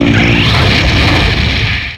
Cri de Pyrax dans Pokémon X et Y.